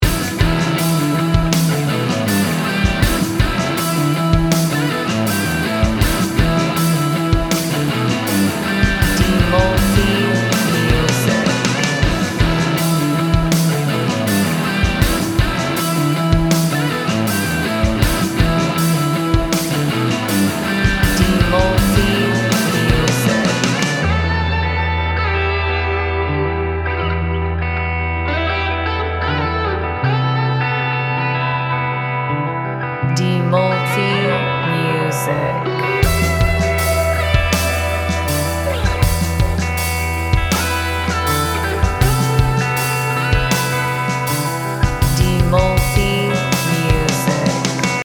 Gym Music Instrumental